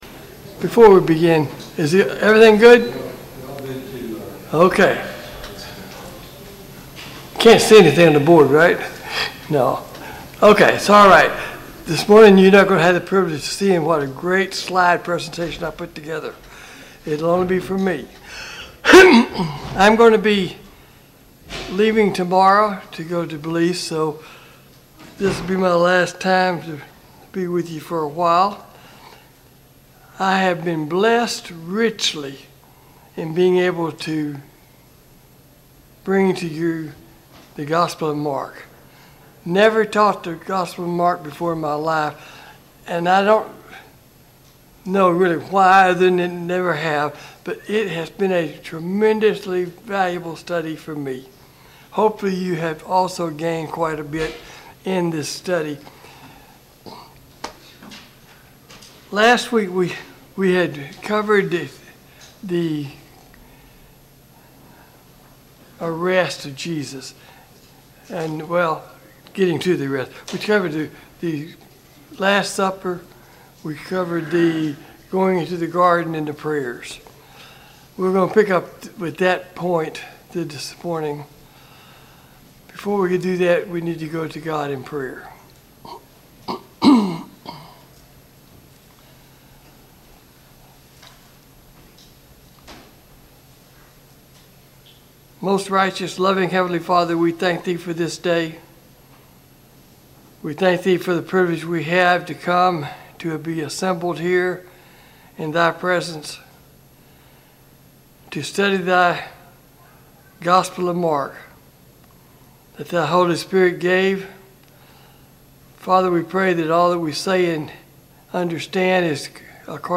Passage: Mark 14-16 Service Type: Sunday Morning Bible Class « Study of Paul’s Minor Epistles